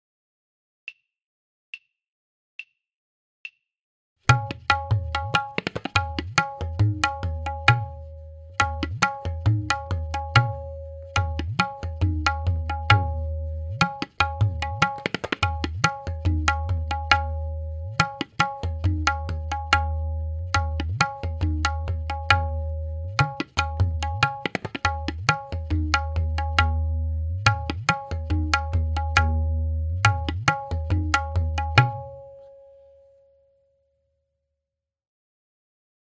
We then play this repetition three times to make our chakradar:
Simple chakradar from kayda
DK2-DhatidhagiNadhatirakita-S_Ck-click.mp3